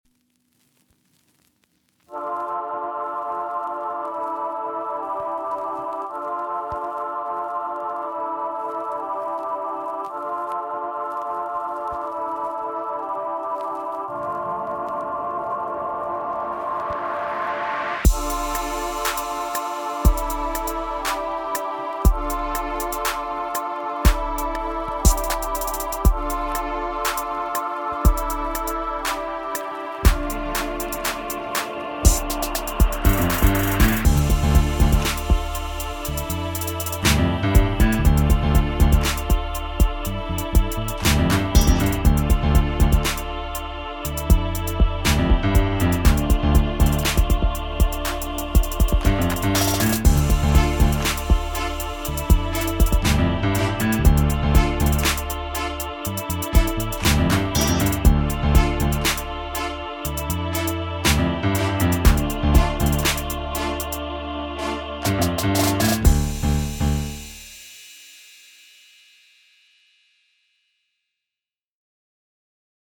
Home > Music > Pop > Medium > Mysterious > Beats